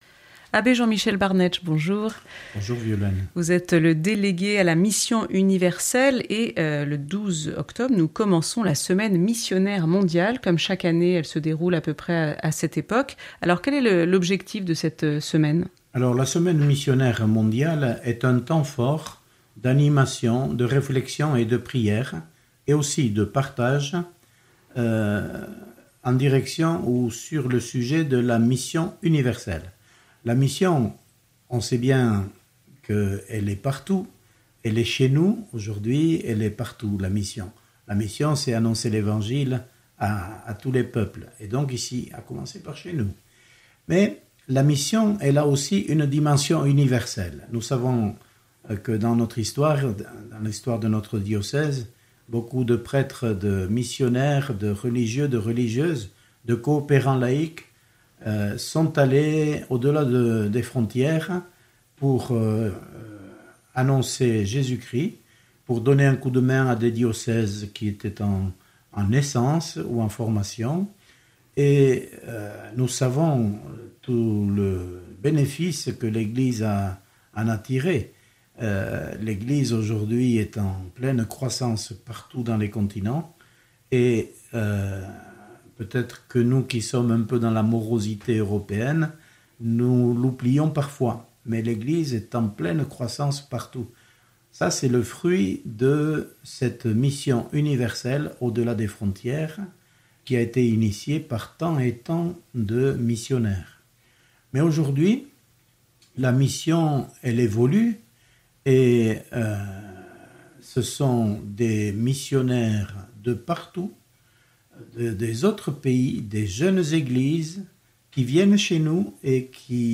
Interviews et reportages